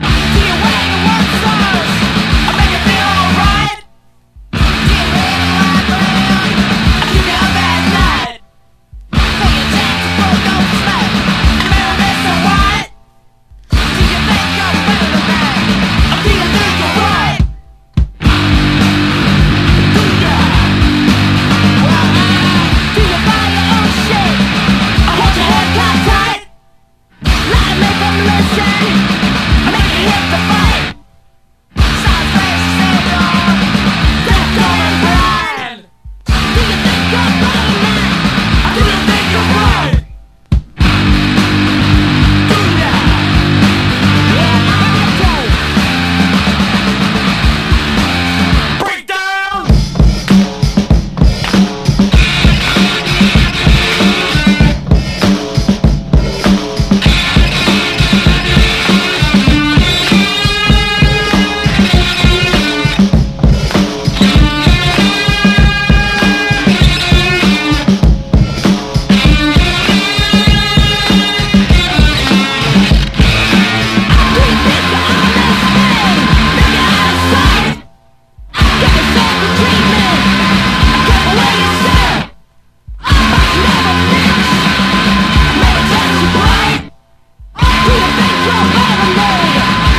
ダブステップとディスコを融合させたようなポストロック/ダブな最高スプリット・シングル！